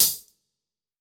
TC3Hat14.wav